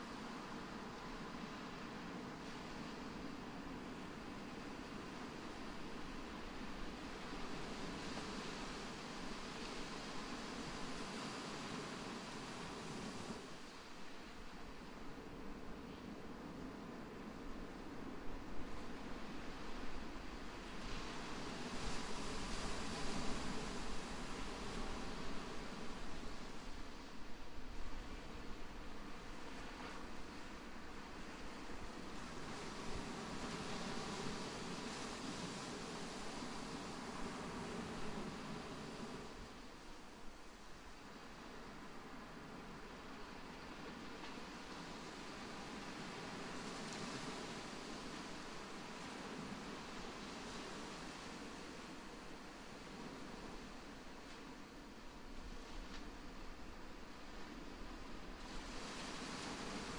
描述：在海滩的小波浪
标签： 海浪 沙滩 海洋
声道立体声